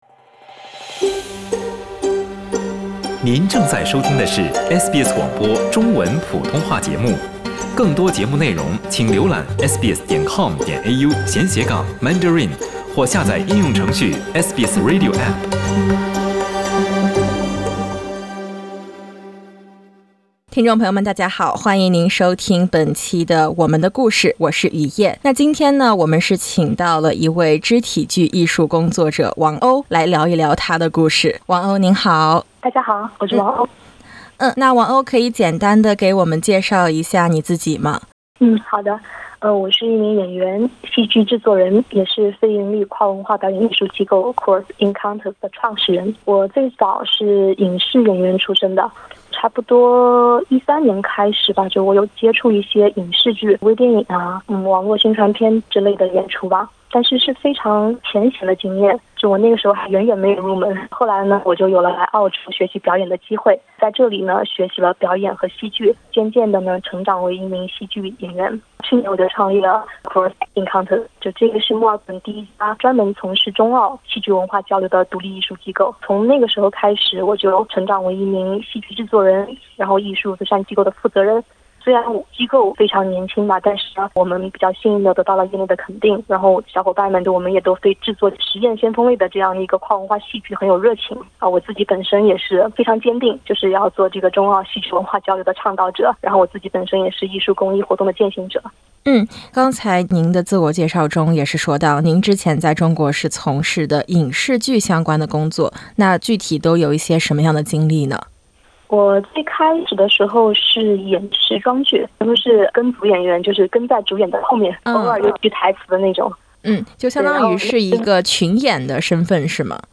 欢迎点击封面音频，收听完整采访。